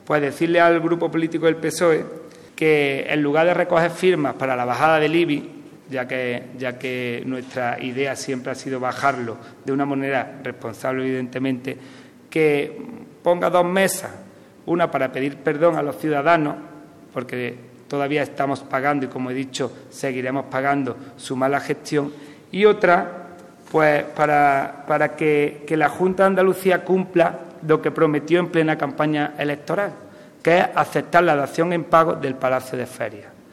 Antequera cuenta con el coeficiente de IBI –único valor sobre el que tienen competencias los ayuntamientos– más bajo de los últimos 15 años: el 0,775. Así lo ha reiterado hoy en rueda de prensa el teniente de alcalde delegado de Hacienda, Juan Rosas, que ha comparecido ante los medios de comunicación con el objetivo de ofrecer una información clara, concisa y veraz a la ciudadanía en torno al asunto de los recibos del IBI del ejercicio 2015 y el “ruido” provocado por ciertas formaciones políticas de la oposición para aprovecharse a toda costa de las circunstancias pese a faltar a la verdad.